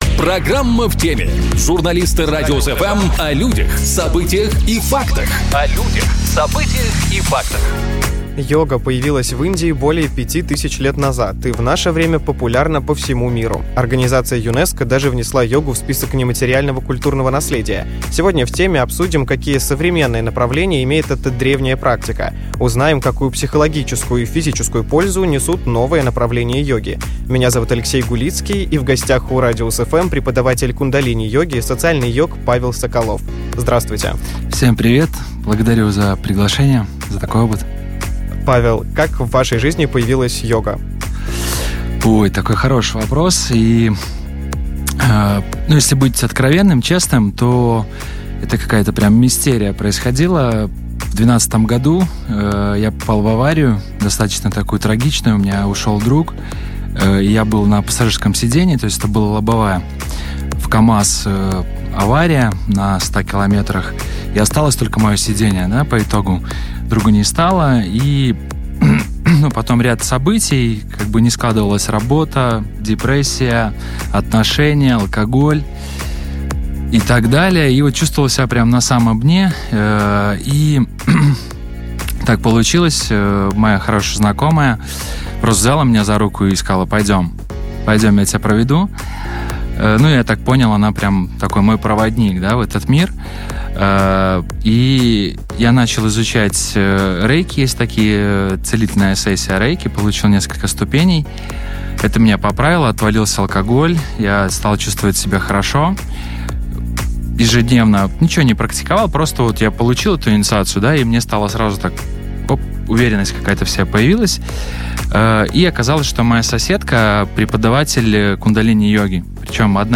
В гостях у "Радиус FM" преподаватель кундалини-йоги